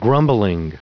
Prononciation du mot grumbling en anglais (fichier audio)
Prononciation du mot : grumbling